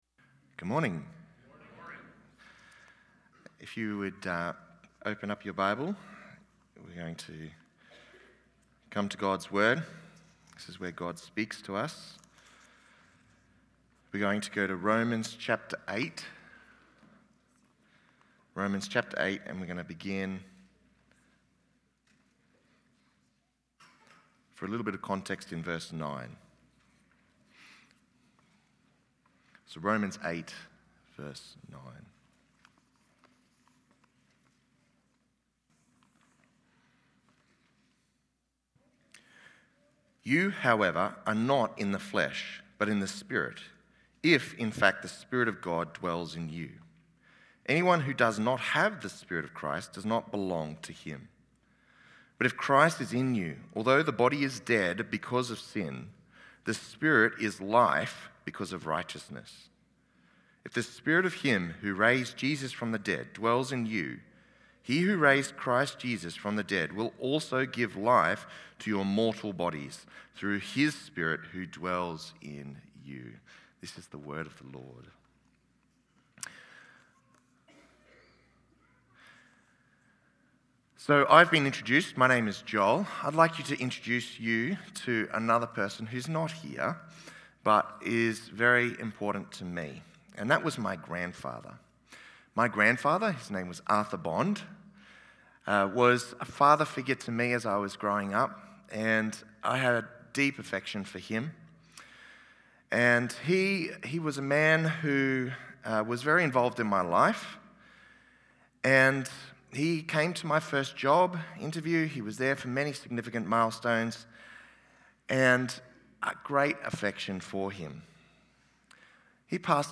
Messages given at the Sunday Morning Celebration Gathering of Sovereign Grace Church Dayton